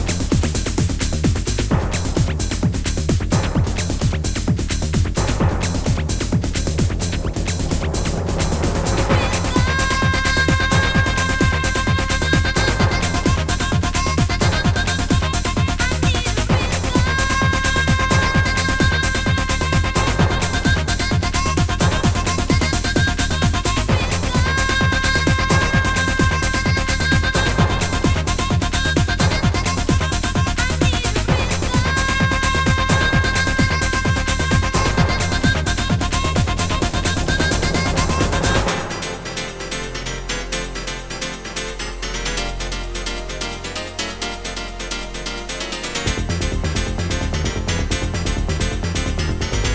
疾走感アリのAcid House